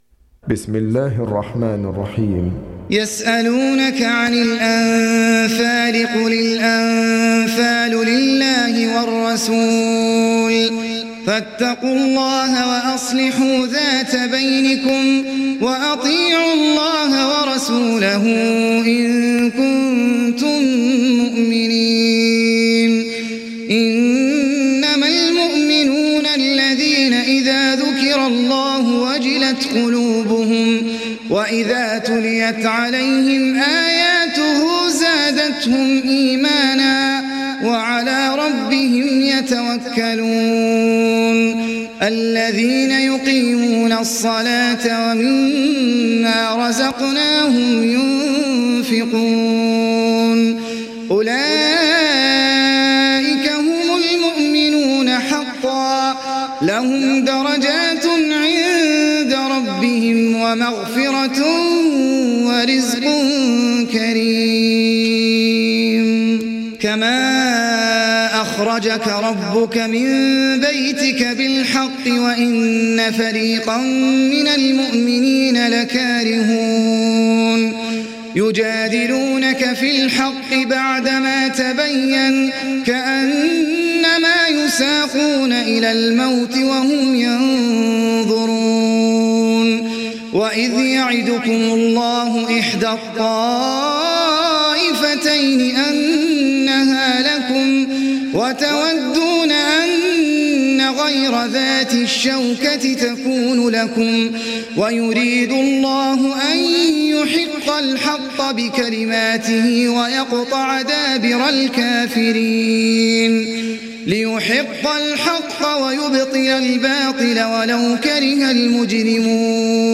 دانلود سوره الأنفال mp3 أحمد العجمي روایت حفص از عاصم, قرآن را دانلود کنید و گوش کن mp3 ، لینک مستقیم کامل